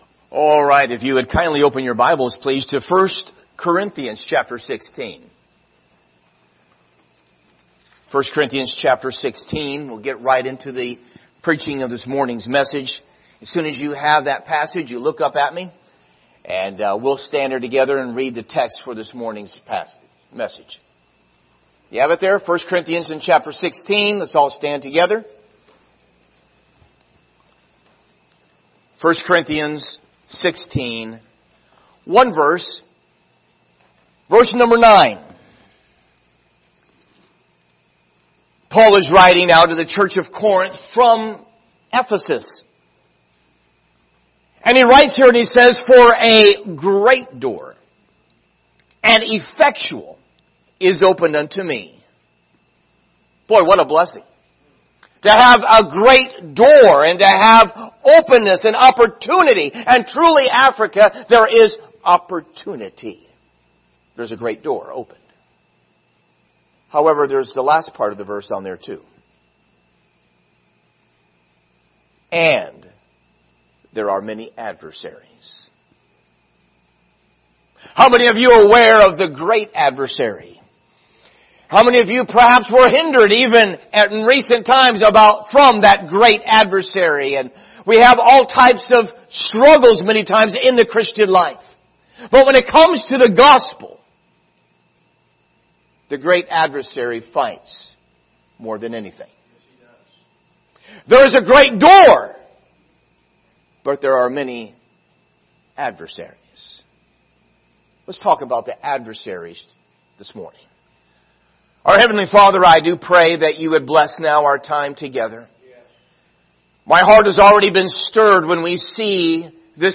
Speaker: Missionary
Service Type: Sunday Morning